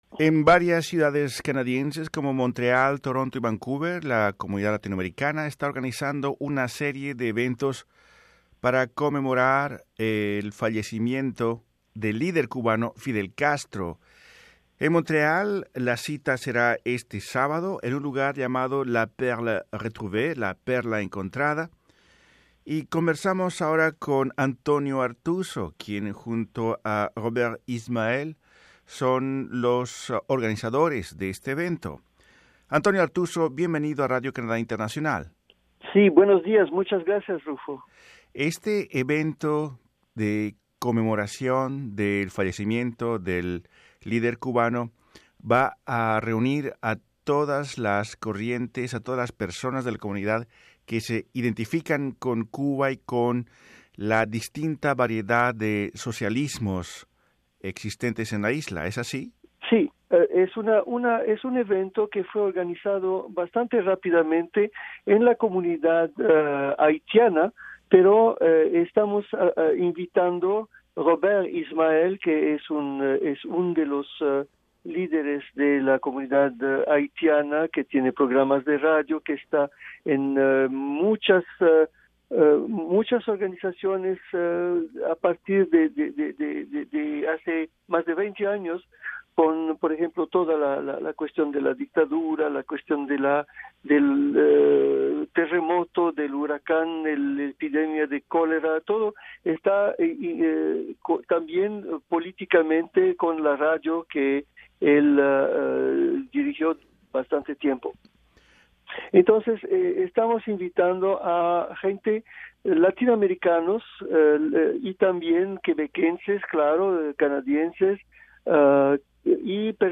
En conversación con Radio Canadá Internacional